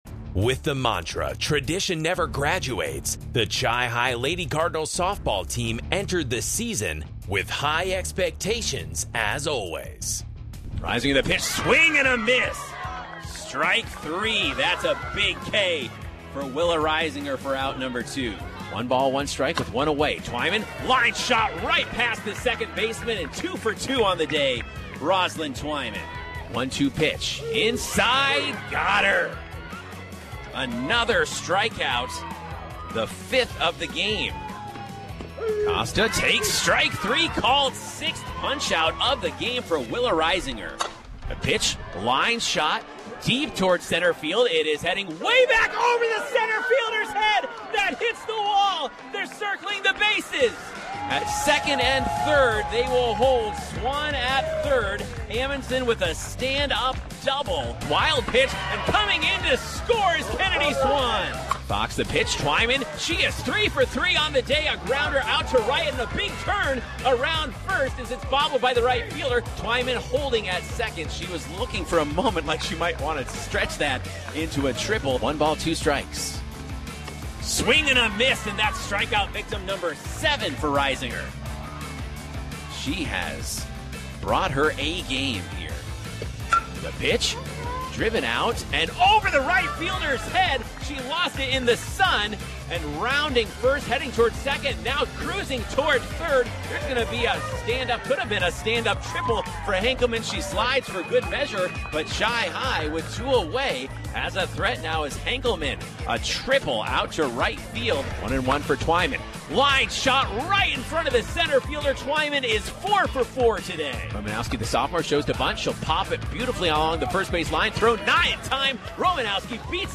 A regular season full of exciting Chippewa Falls Lady Cardinals softball on WOGO Sports! Take a listen to some of our favorite moments!